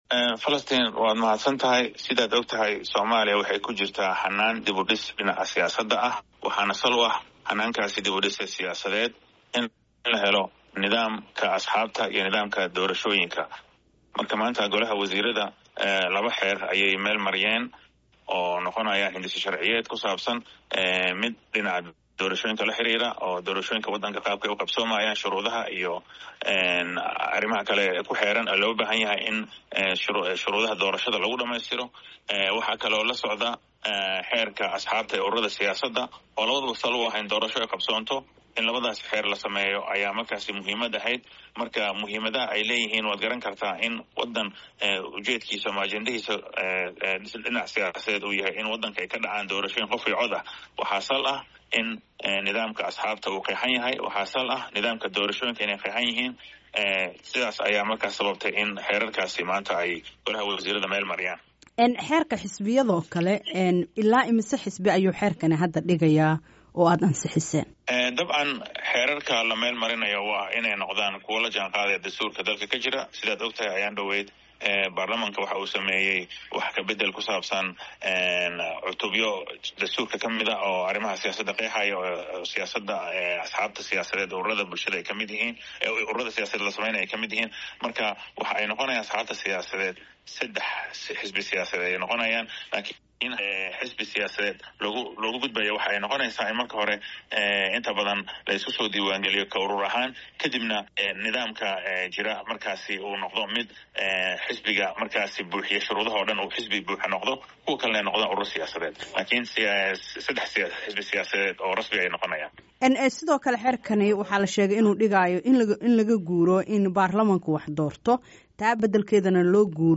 Waraysiga wasiirka warfaafinta